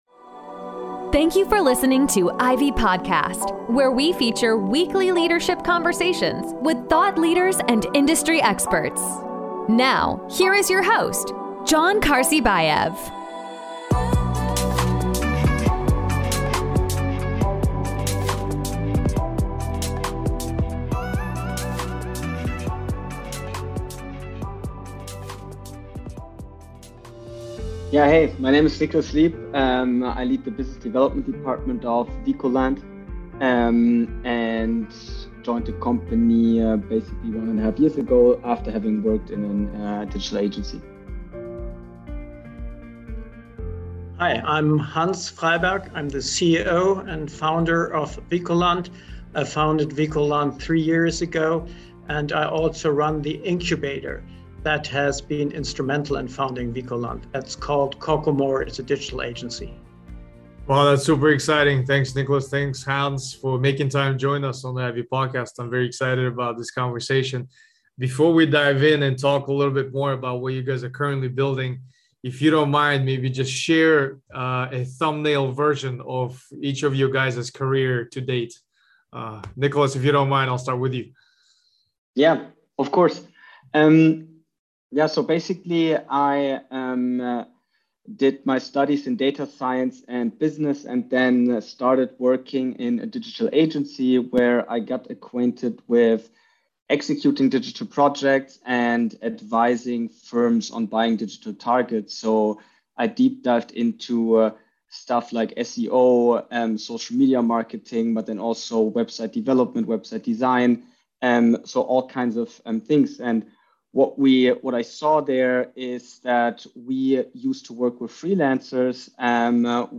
Conversation on Freelance Communities, Worker Classification and Winning the War on Retention